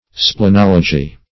Search Result for " splenology" : The Collaborative International Dictionary of English v.0.48: Splenology \Sple*nol"o*gy\, n. [Gr. splh`n spleen + -logy.] The branch of science which treats of the spleen.